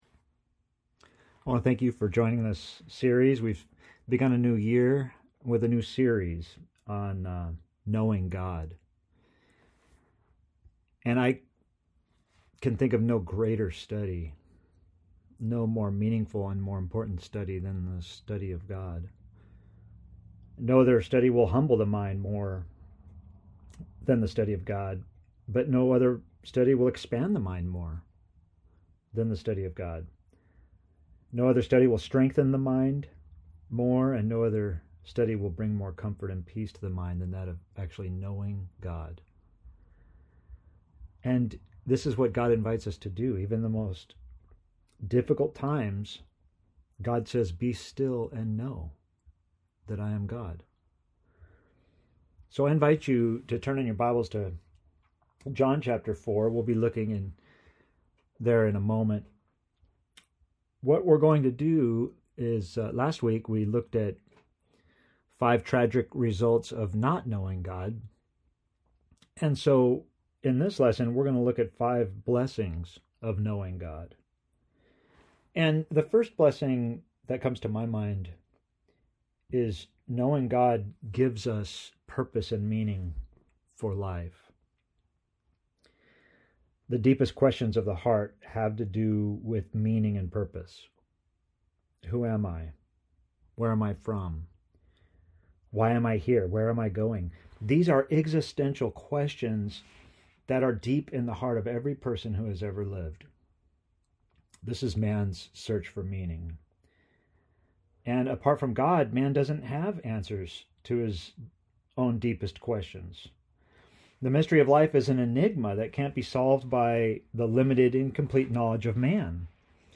Pre-recorded for Sunday 1/23/21